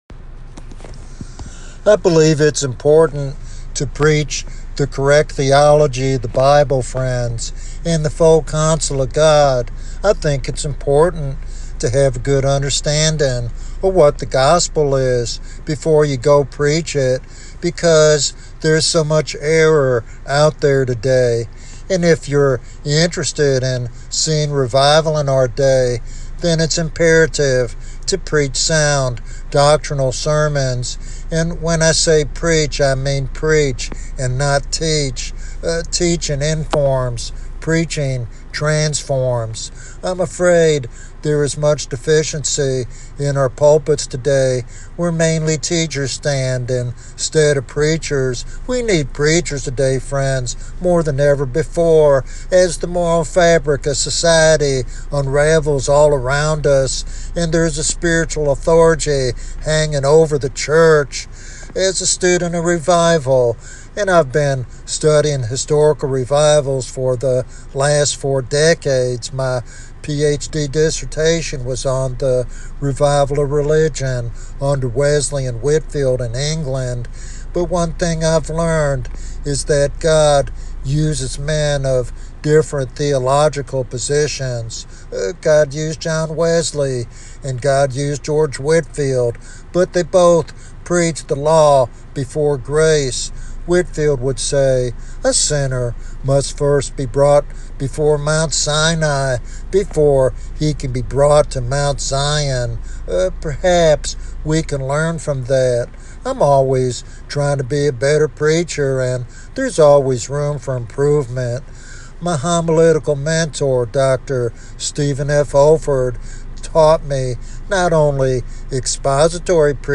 This sermon is a passionate appeal for preachers to carry the fire of anointed proclamation in a world desperate for spiritual renewal.